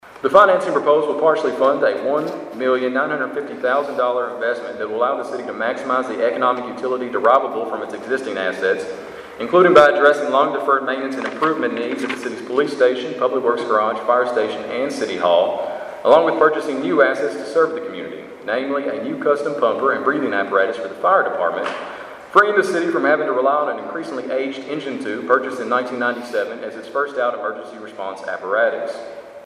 Princeton Mayor Kota Young